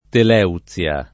[ tel $ u ZZL a ]